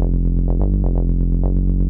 • Sistained Tech House Bass Rhythm - EDM - Fm - 126.wav
Sistained_Tech_House_Bass_Rhythm_-_EDM_-_Fm_-_126_P0L.wav